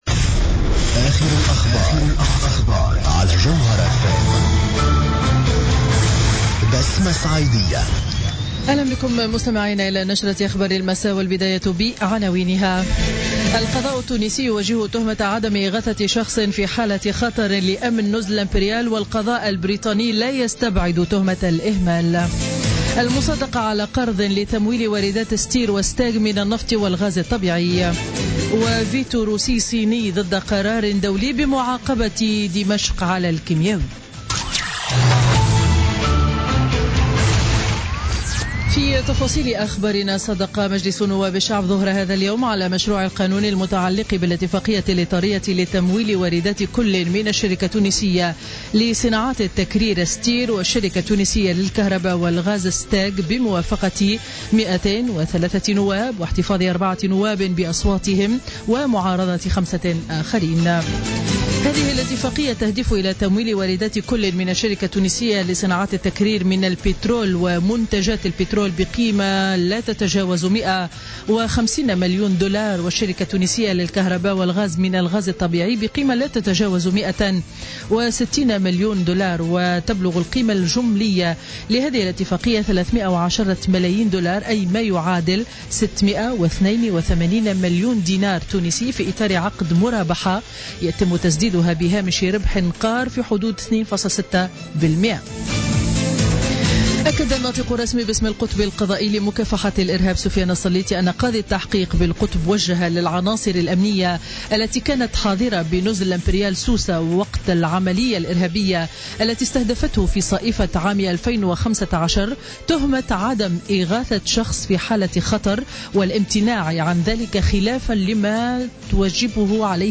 نشرة أخبار السابعة مساء ليوم الثلاثاء 28 فيفري 2017